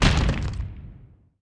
drop_1.wav